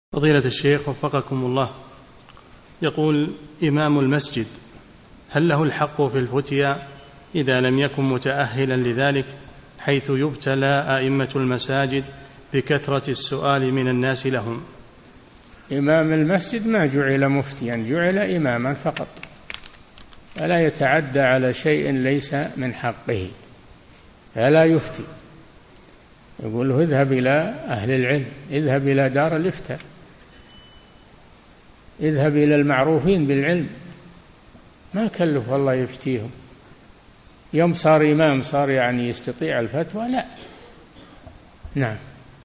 ملف الفتوي الصوتي عدد الملفات المرفوعه : 1